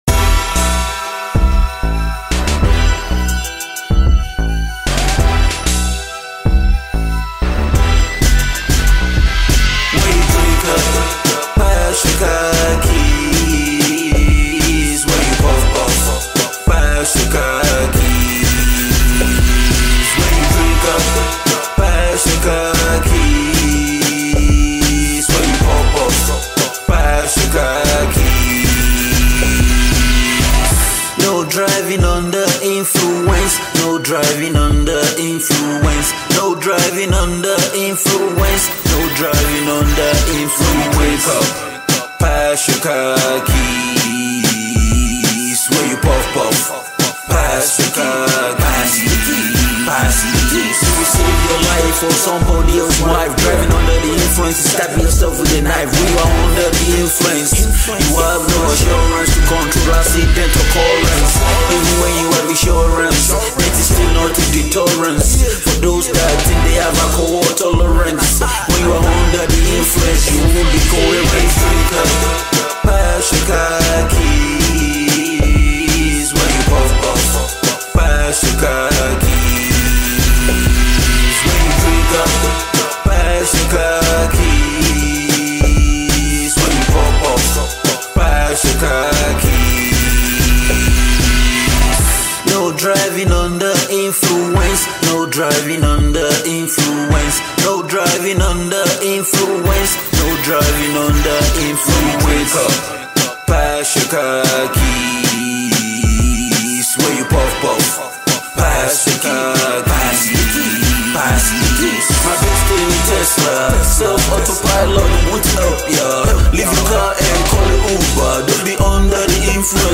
Chart-topping Nigerian Gospel singer